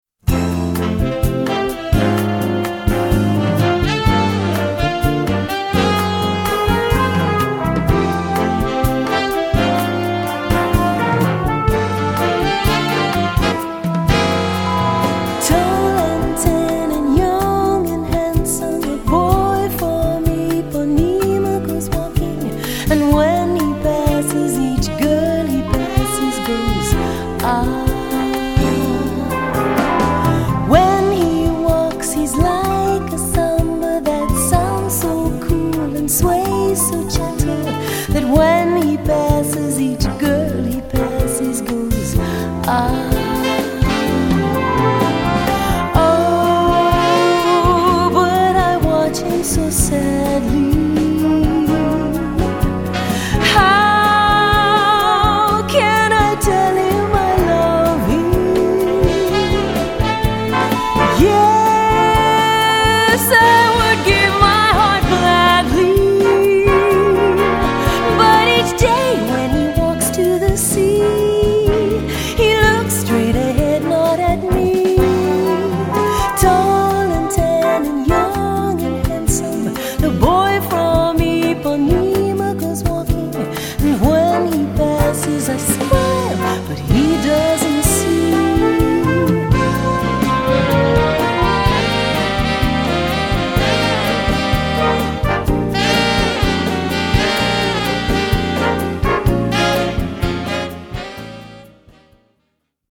Gattung: Big Band und Gesang
Besetzung: Big-Band-Noten